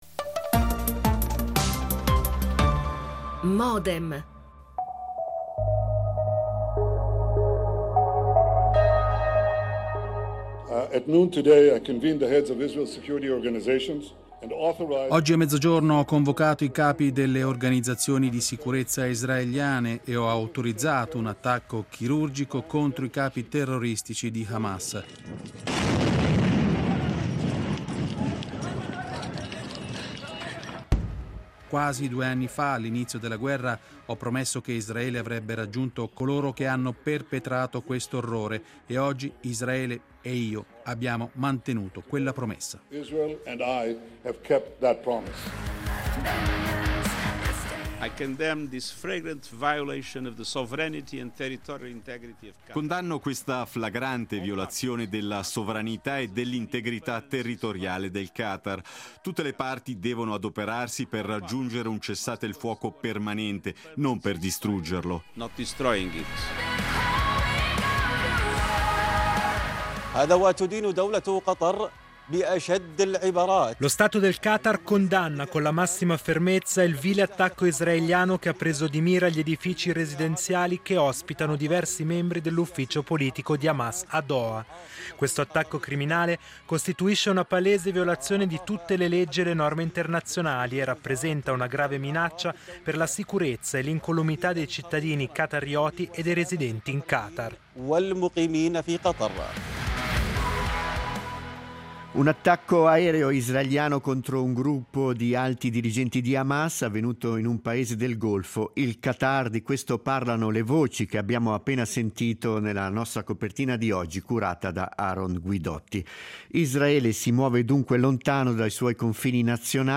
Ne parliamo con tre ospiti
L'attualità approfondita, in diretta, tutte le mattine, da lunedì a venerdì